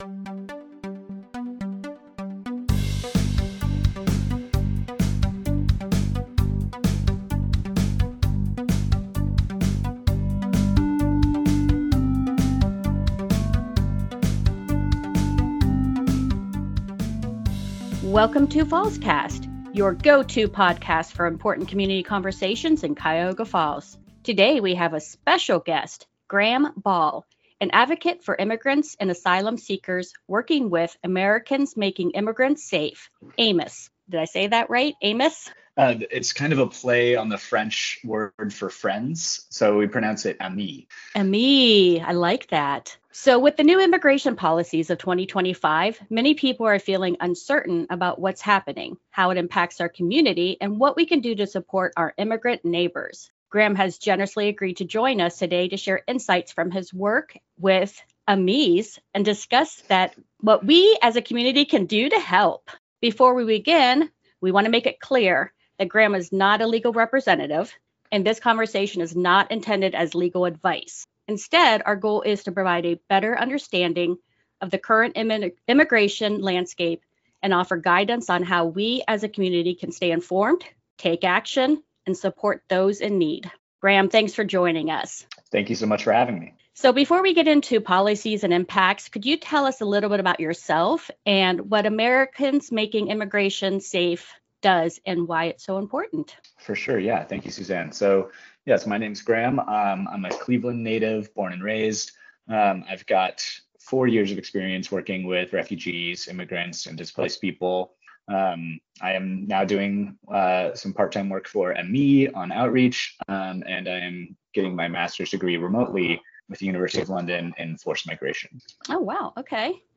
Fallscast Bonus Interview